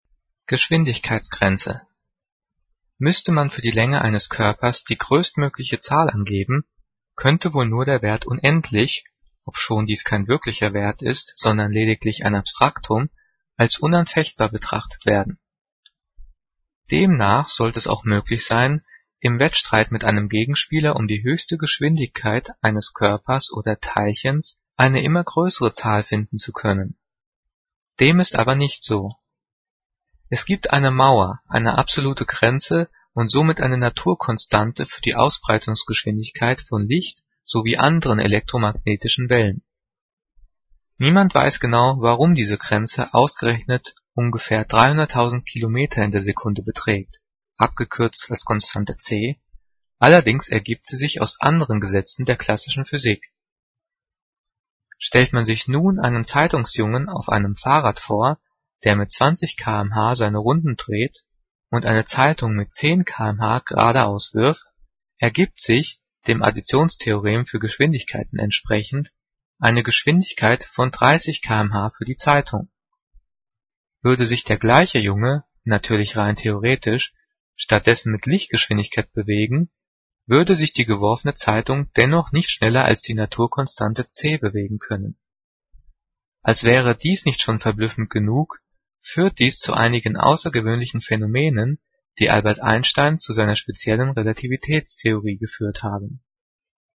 Diktat: "Geschwindigkeitsgrenze" - 9./10. Klasse - Zeichensetzung
Gelesen: